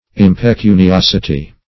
Search Result for " impecuniosity" : The Collaborative International Dictionary of English v.0.48: Impecuniosity \Im`pe*cu`ni*os"i*ty\, n. The state of being impecunious.